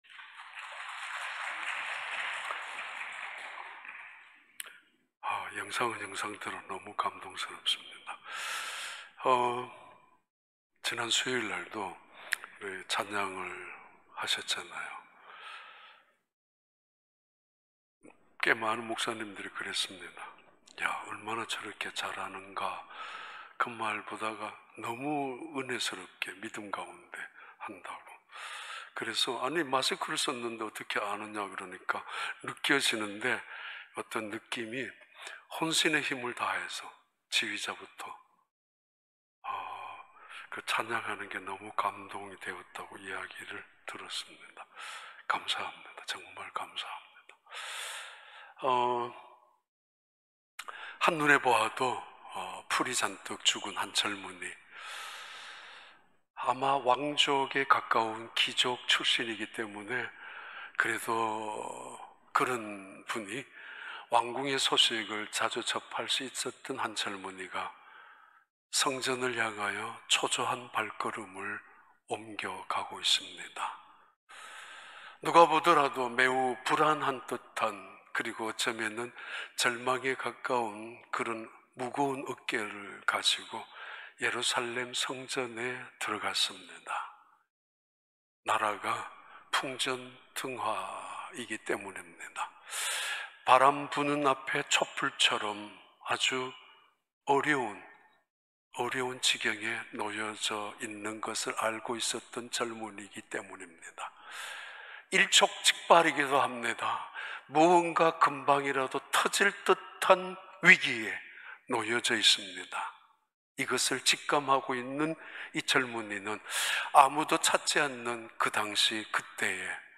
2021년 5월 23일 주일 4부 예배